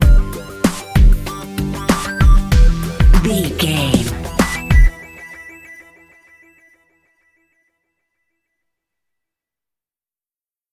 Aeolian/Minor
drums
electric piano
strings
hip hop
Funk
neo soul
acid jazz
energetic
bouncy
funky